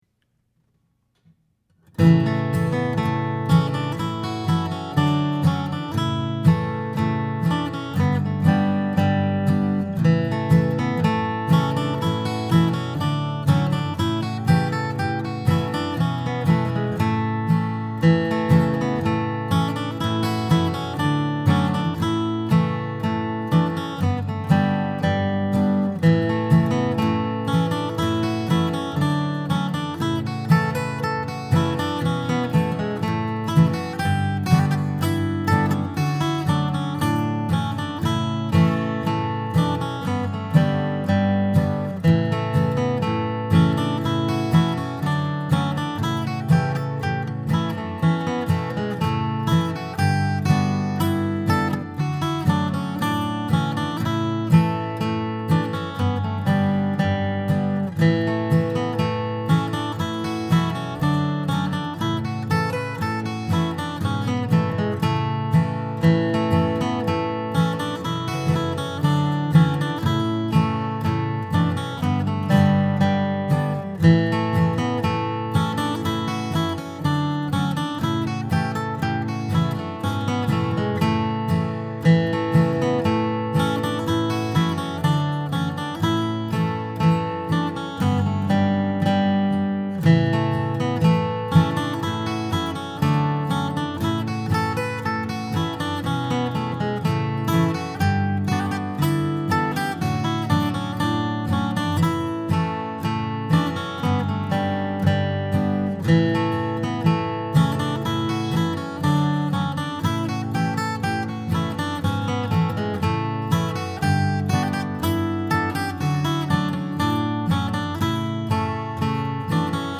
RED HAIRED BOY | GUITAR
Red Haired Boy (60 bpm) (.mp3 file)
RedHairedBoy60_guitar.mp3